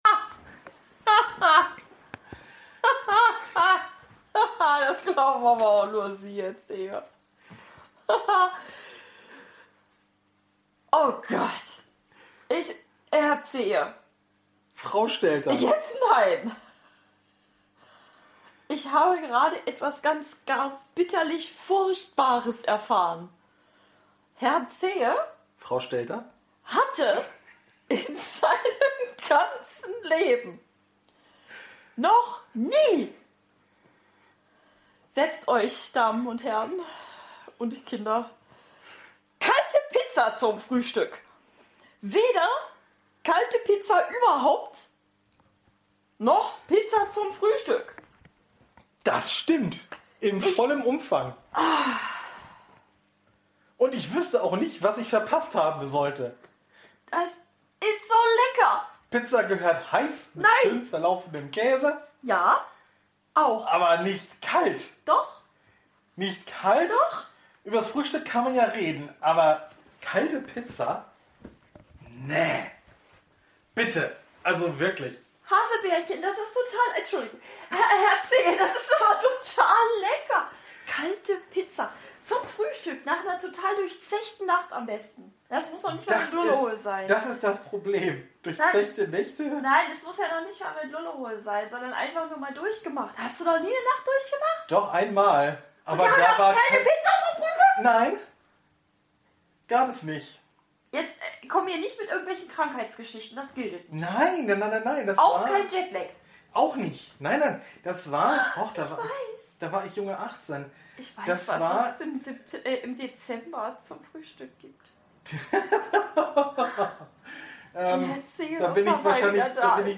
Mit ein bisschen Sprachlosigkeit und Gesinge.